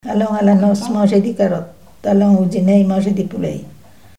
formulette enfantine : amusette
Témoignages sur les folklore enfantin
Pièce musicale inédite